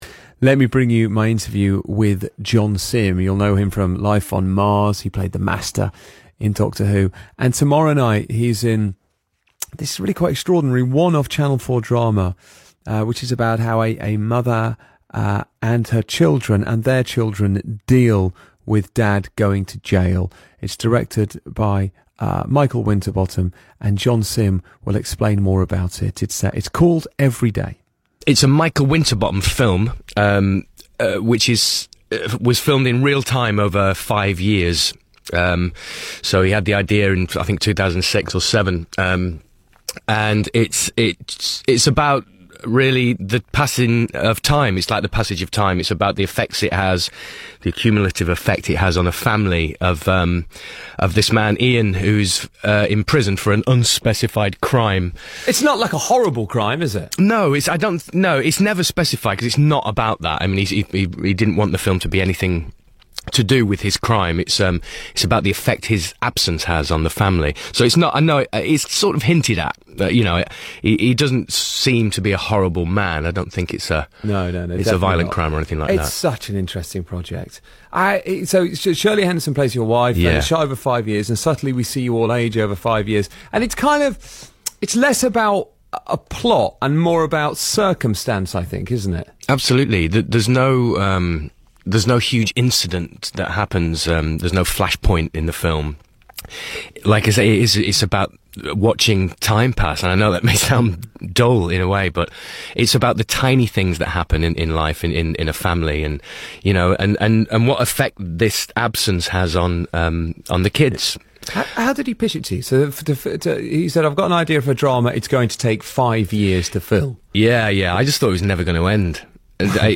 Radio Interview: Richard Bacon talks to John Simm